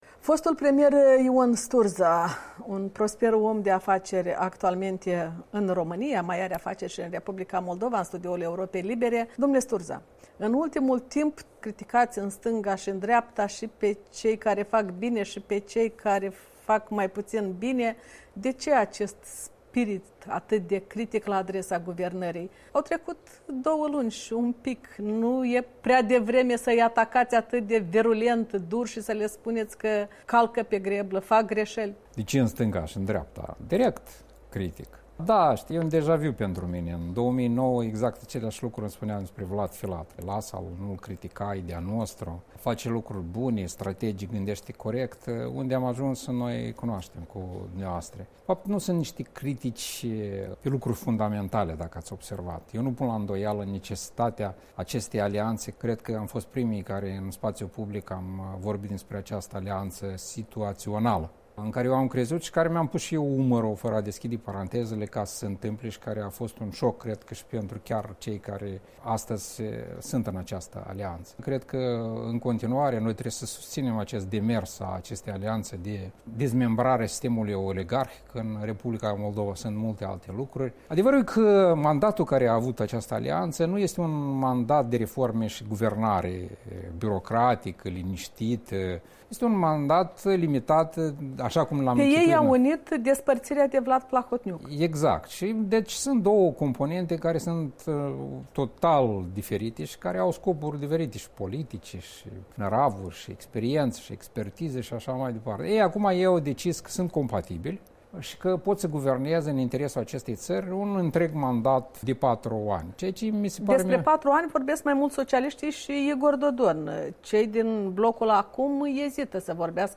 Un interviu cu omul de afaceri, fost prim-ministru al R. Moldova.